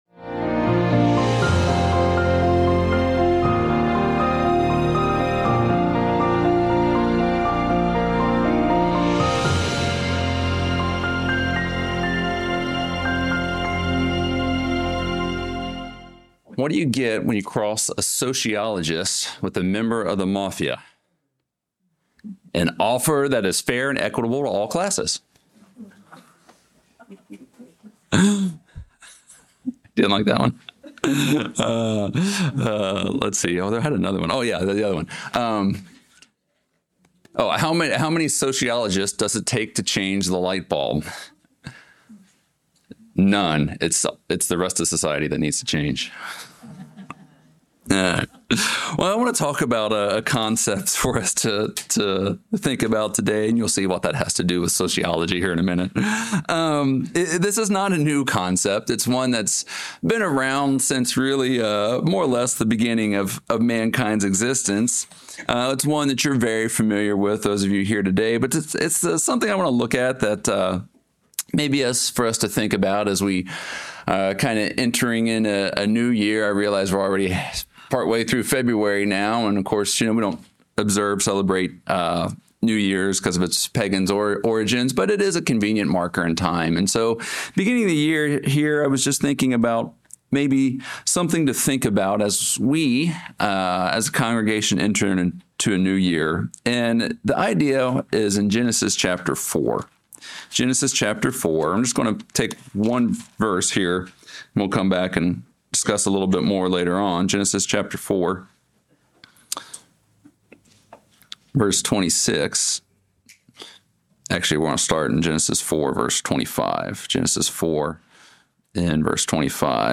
Sermons
Given in Charlotte, NC Hickory, NC Columbia, SC